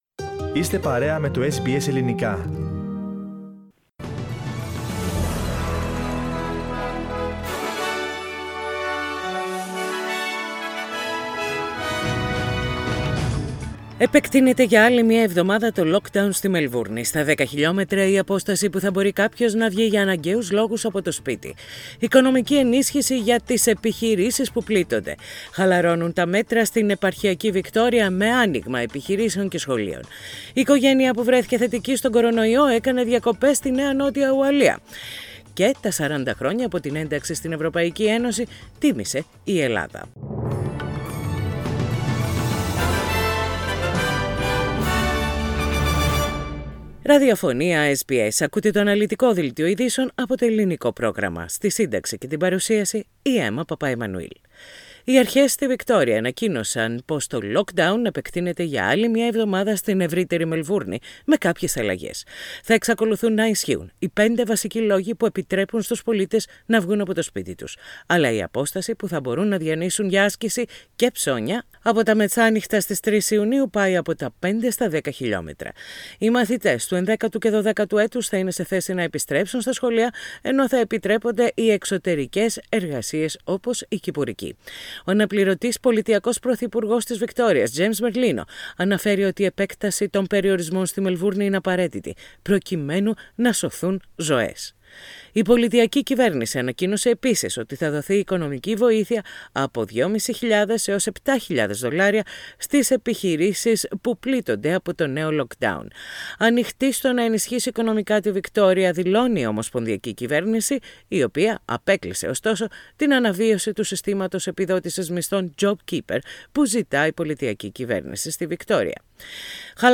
Press Play on the main photo and listen the News Bulletin (in Greek) Source: SBS Greek